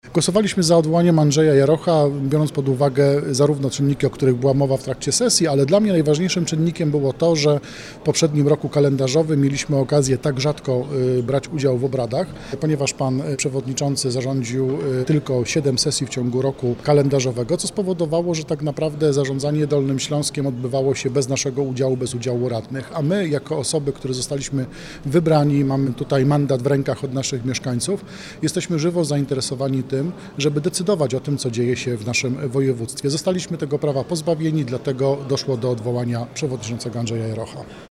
Radny Jakub Iwancz z klubu Nowa PL tłumaczy, dlaczego radni ugrupowania zagłosowali za odwołaniem Andrzeja Jarocha z funkcji przewodniczącego.